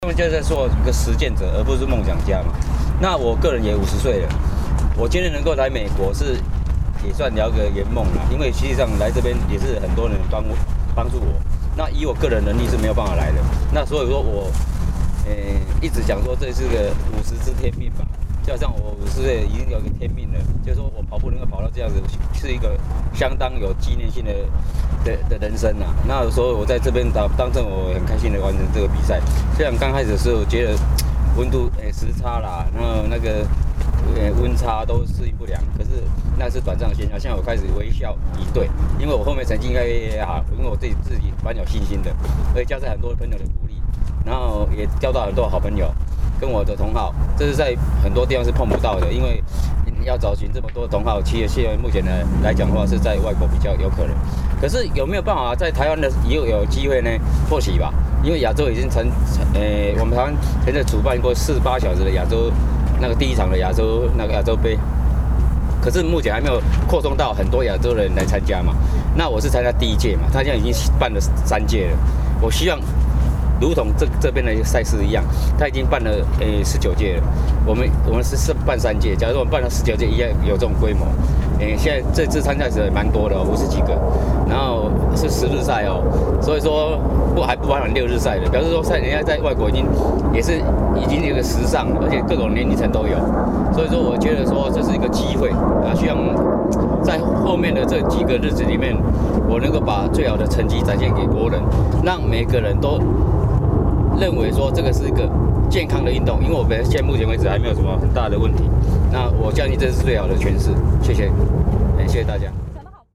Taiwanese Interview
Taiwanese-Interview1.mp3